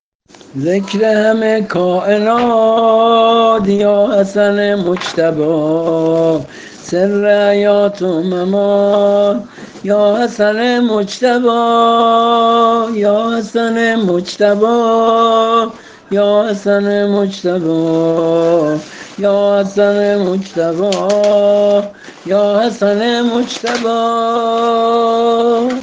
◾نوحه‌ی زمینه سینه زنی